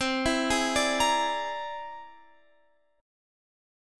C Augmented 9th